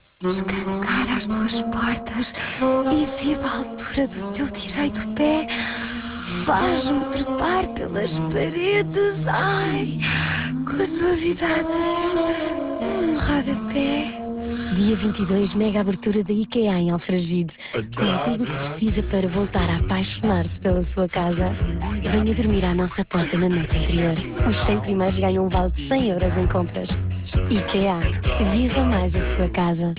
A presença em rádio iniciou-se dia 14 de Junho também contando com dois spots de 30 segundos cada.